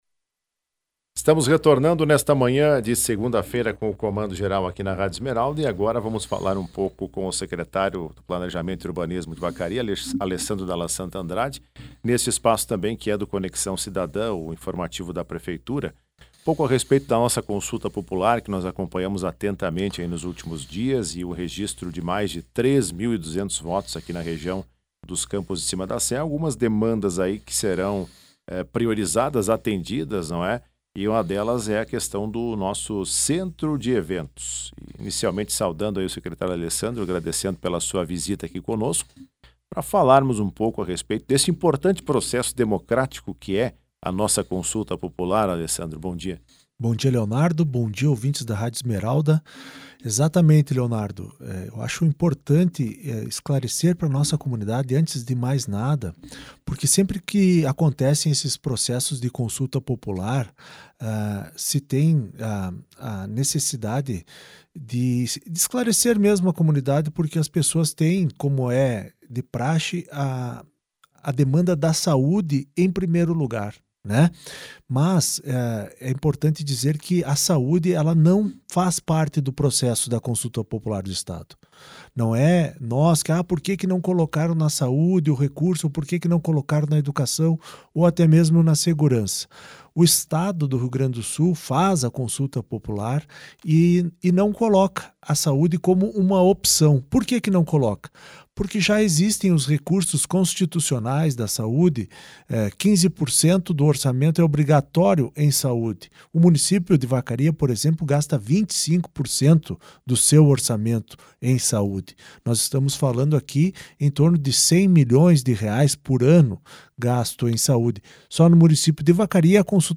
Em entrevista ao programa Comando Geral desta segunda-feira, o secretário municipal de Planejamento e Urbanismo de Vacaria, Alessandro Dalla Santa Andrade, explicou que a Consulta Popular não pode incluir propostas nas áreas da saúde e educação, pois essas já possuem investimentos mínimos garantidos por lei.